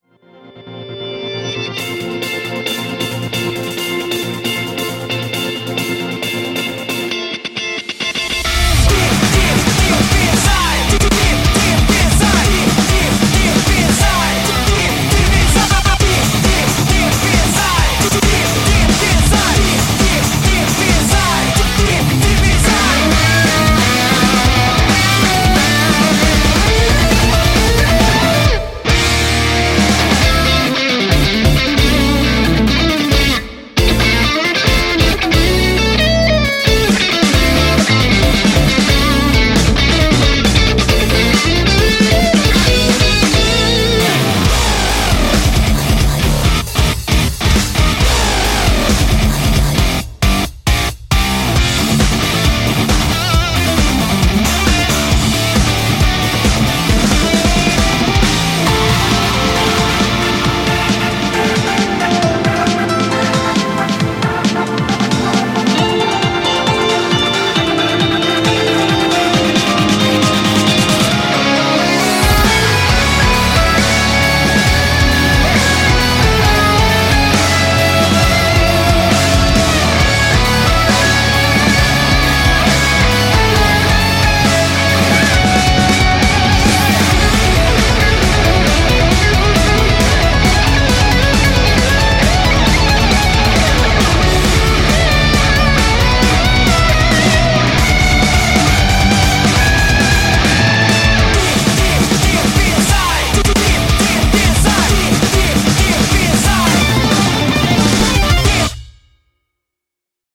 BPM135
Audio QualityPerfect (High Quality)
powerful melody that gives it a really climactic feeling